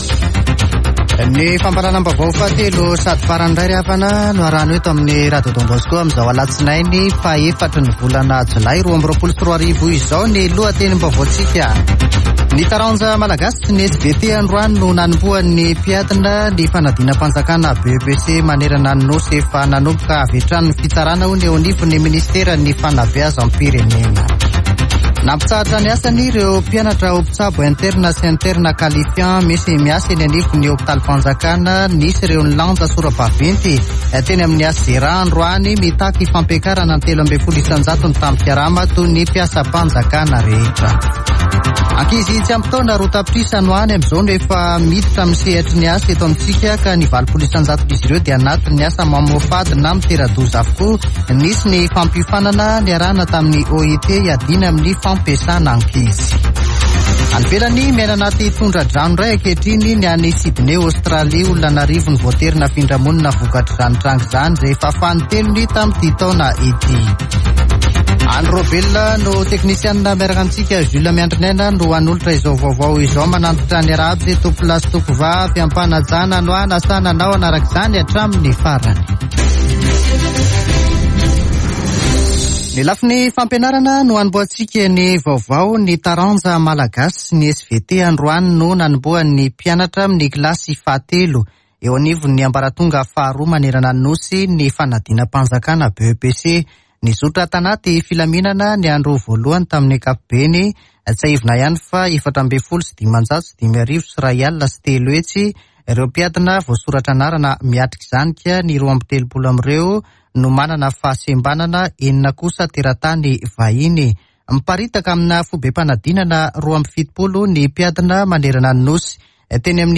[Vaovao hariva] Alatsinainy 4 jolay 2022